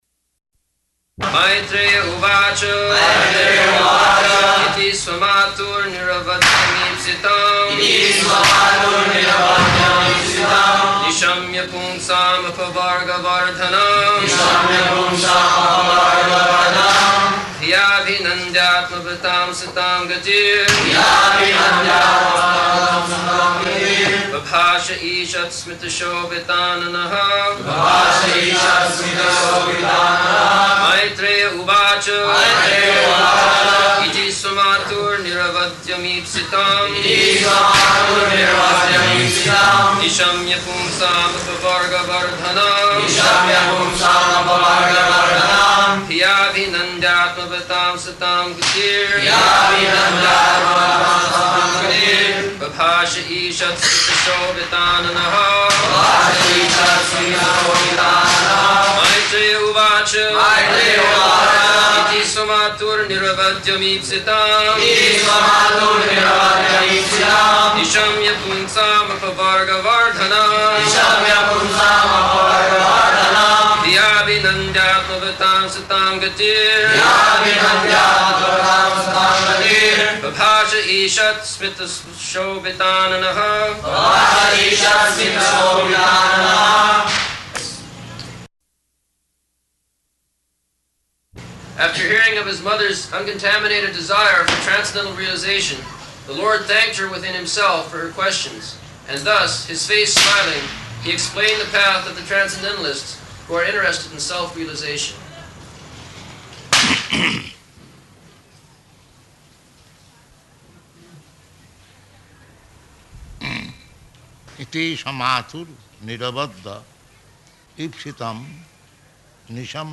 November 12th 1974 Location: Bombay Audio file
[noise of fireworks in background]
[devotees repeat]